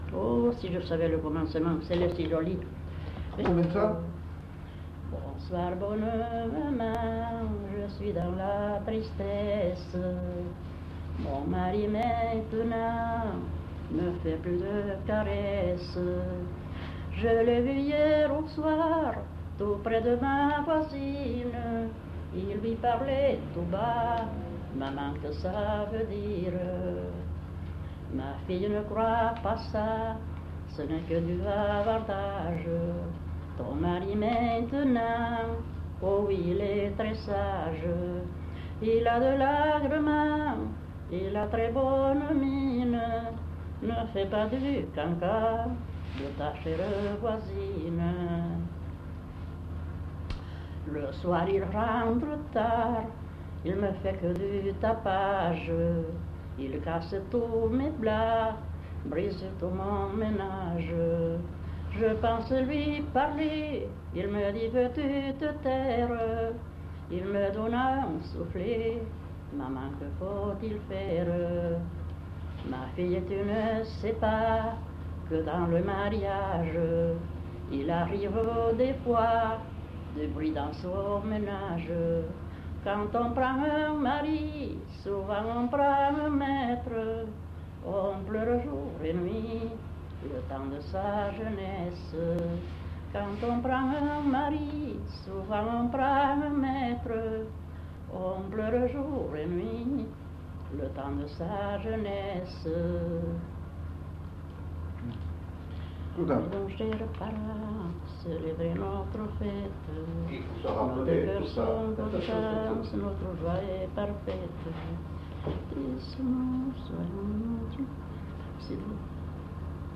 Aire culturelle : Marsan
Genre : chant
Effectif : 1
Type de voix : voix de femme
Production du son : chanté
Classification : maumariées